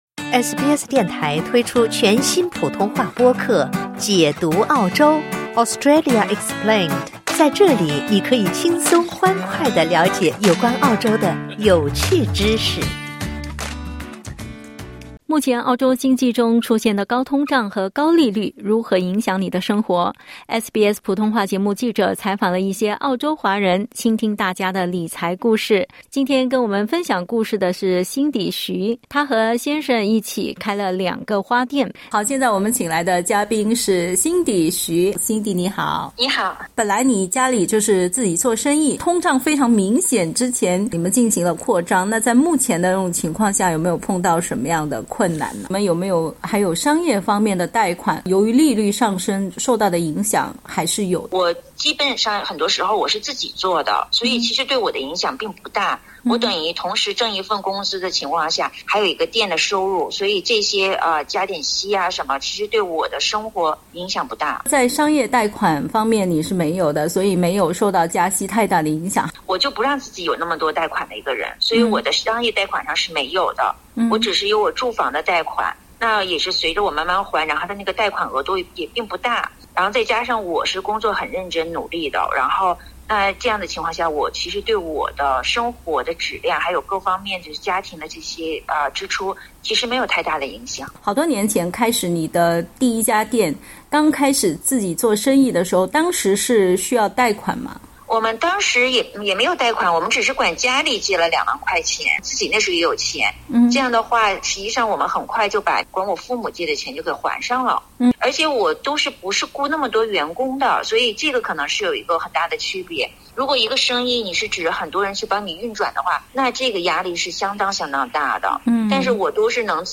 目前澳大利亚的高通胀和高利率如何影响你的生活？SBS普通话记者采访了一些澳洲华人，请他们分享自己在这个特殊阶段做出的理财策略和生活故事。